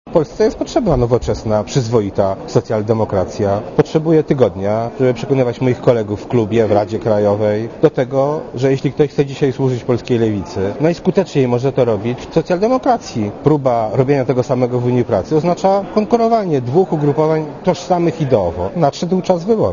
Posłuchaj, co Tomasz Nałęcz mówi o SDPL
Tomasz Nałęcz , który spotkał się w Sejmie z dziennikarzami uważa, że dla członków Unii Pracy nadszedł czas wyboru ale do decyzji o przyłączeniu się do Socjaldemokracji Polskiej każdy musi dojrzeć indywidualnie .